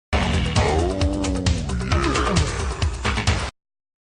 oh-yeah-sound-effect-hd-download.mp3